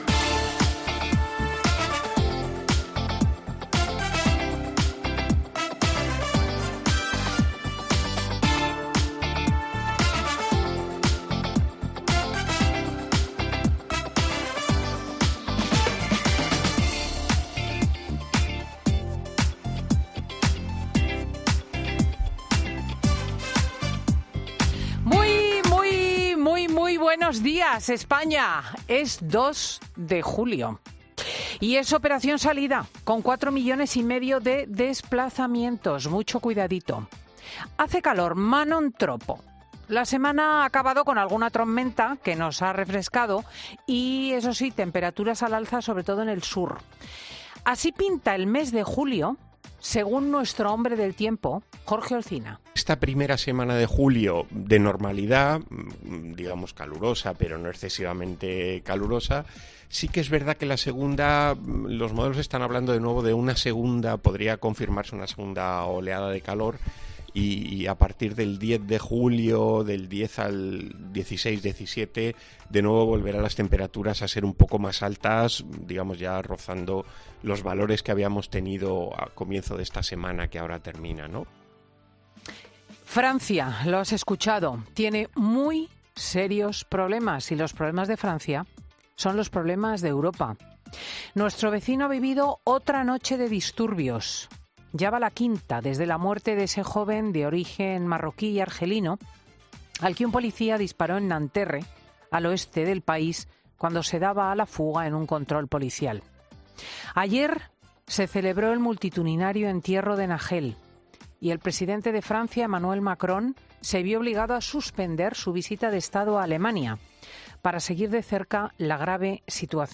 Monólogo de Cristina López Schlichting
Escucha aquí el editorial completo de Cristina L. Schlichting de Fin de Semana del domingo 2 de julio